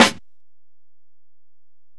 Snare (24).wav